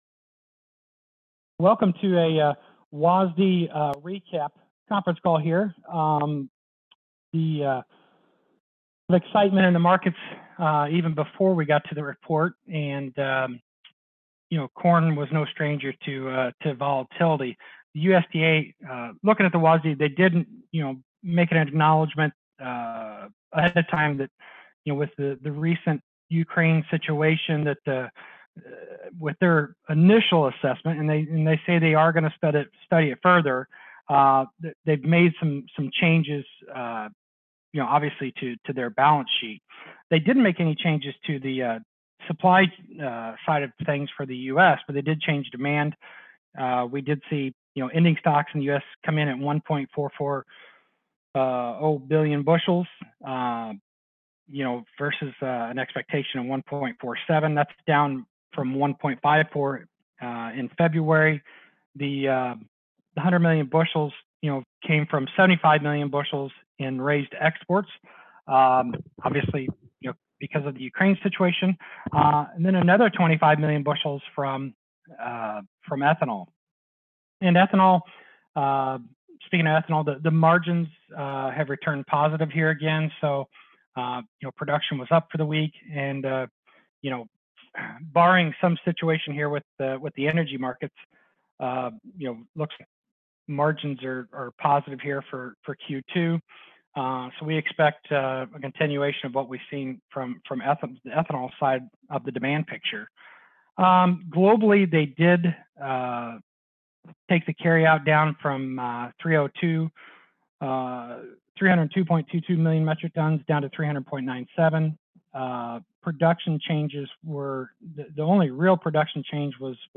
USDA Report Conference Call – John Stewart & Associates (3/9/2022)